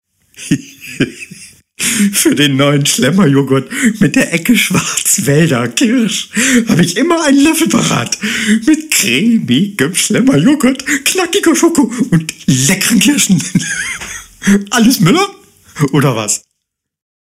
Kein Dialekt
Sprechprobe: Werbung (Muttersprache):